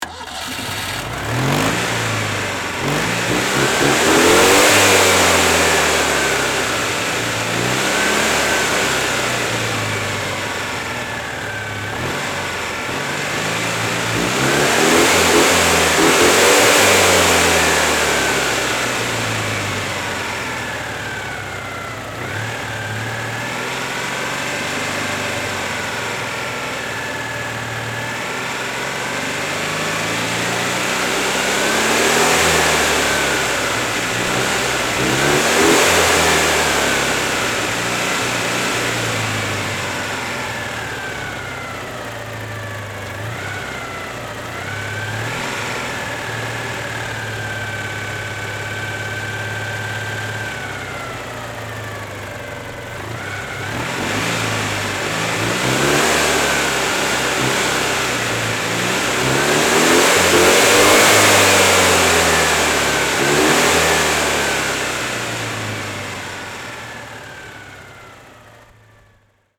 CITROEN 2CV6 Charleston – engine
• Car
• Engine